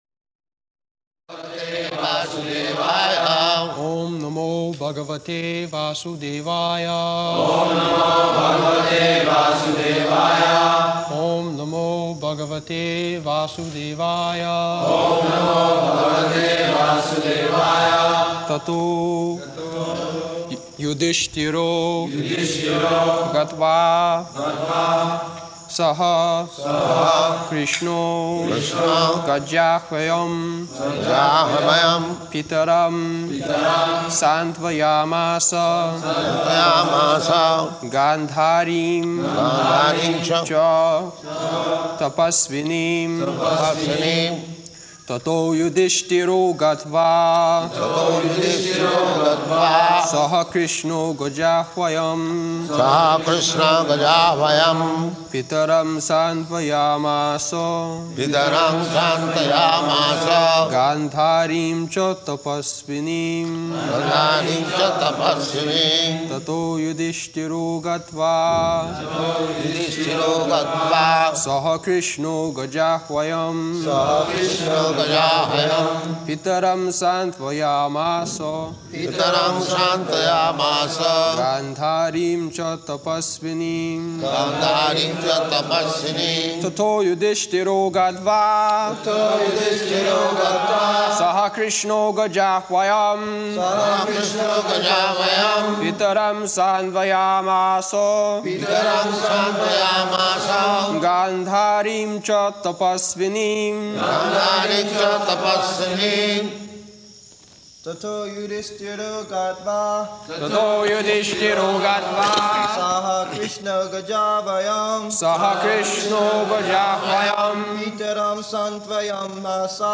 June 14th 1973 Location: Māyāpur Audio file
[leads chanting of verse] [Prabhupāda and devotees repeat]